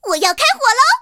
T-127夜战攻击语音.OGG